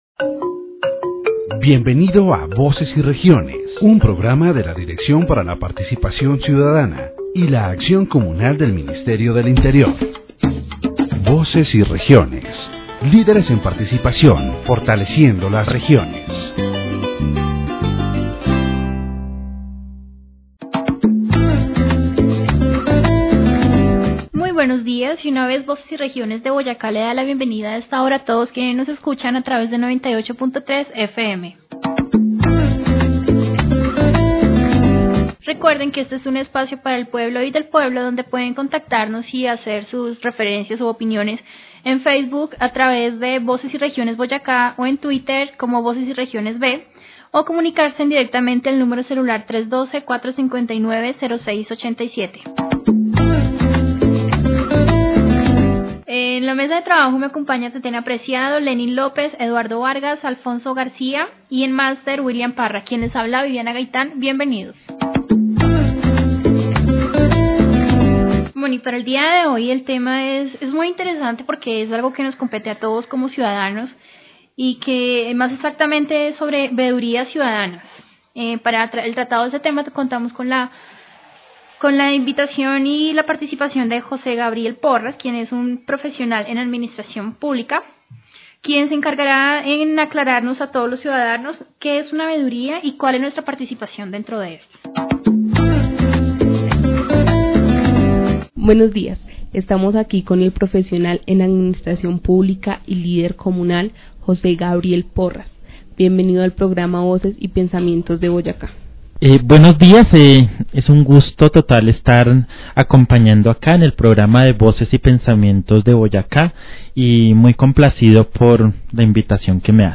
This radio program “Voces y Regiones” addresses the issue of citizen oversight.